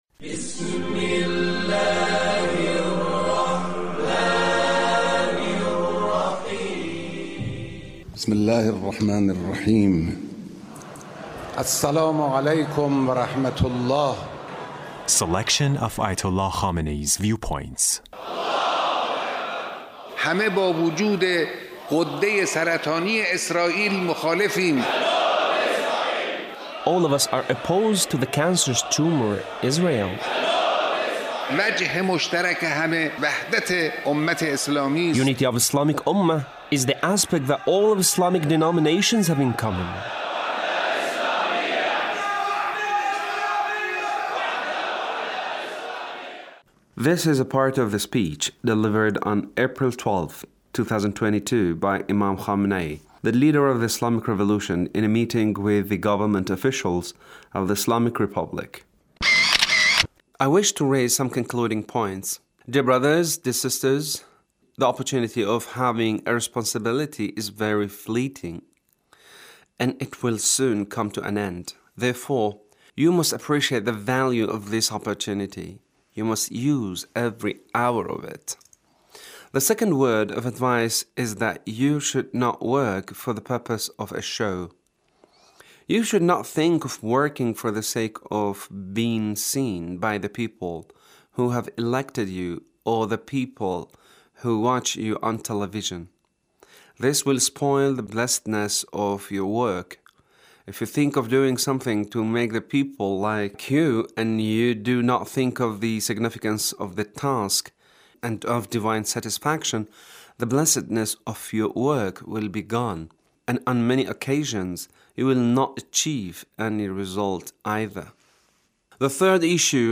Leader's speech (1399)
The Leader's speech in a meeting with Government Officials